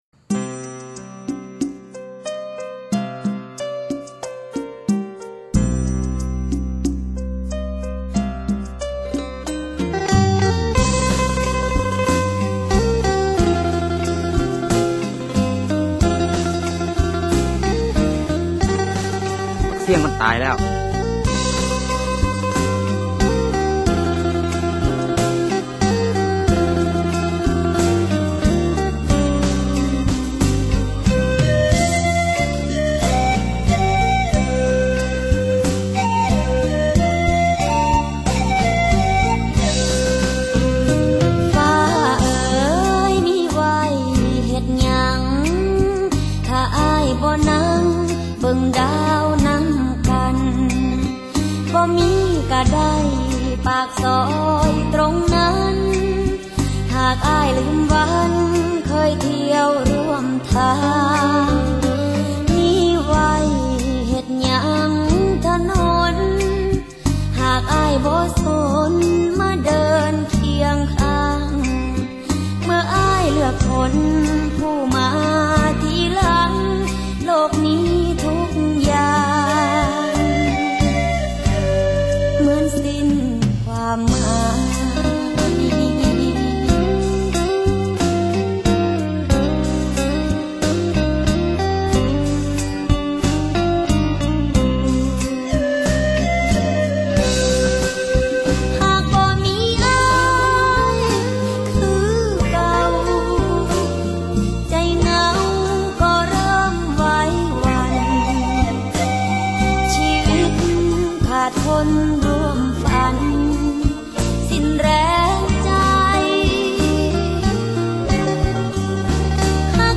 泰国女孩唱的，嗓音很美！